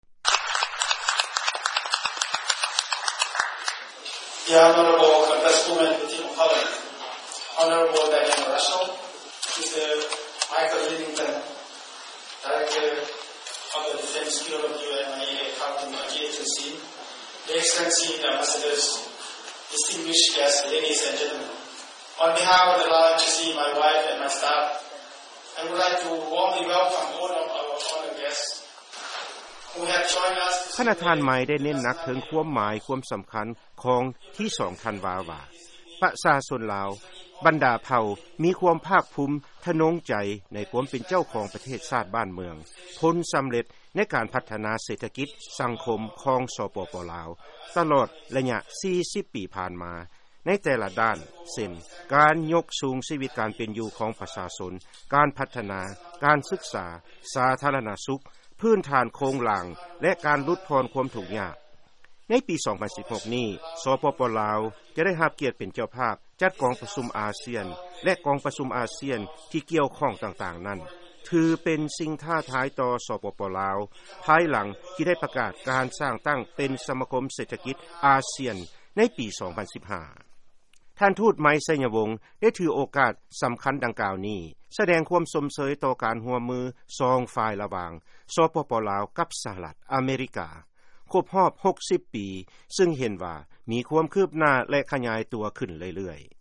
ເຊິນຟັງຄຳຖະແຫລງ ພະນະທ່ານ ເອກອັກຄະລັດຖະທູດ ສປປ ລາວ ໄມ ໄຊຍະວົງ